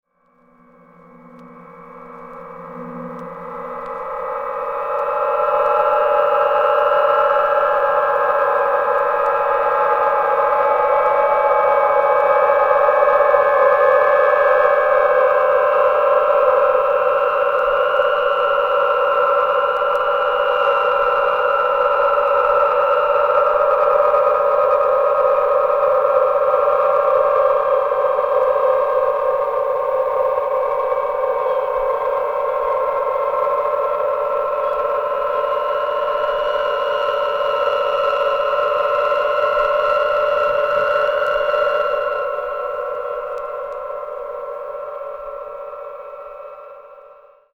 キーワード：音響彫刻　自然音　ドローン